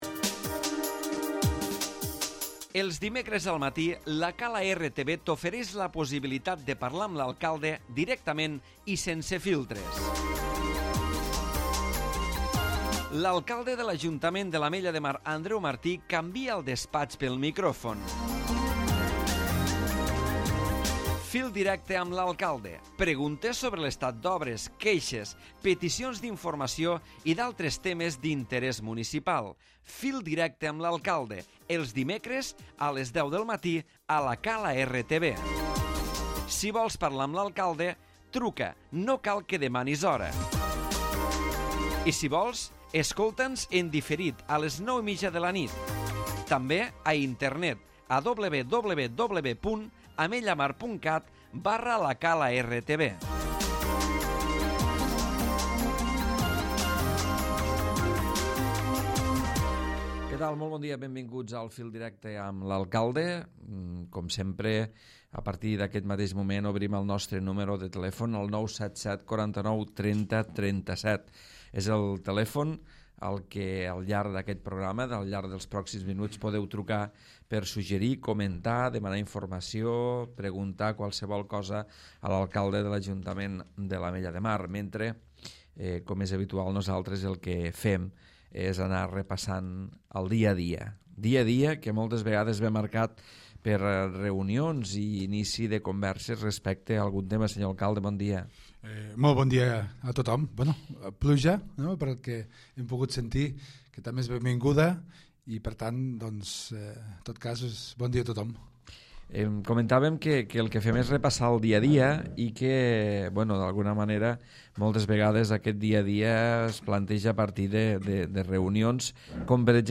Andreu Martí, alcalde de l'Ajuntament de l'Ametlla de Mar repassa el dia a dia municipal.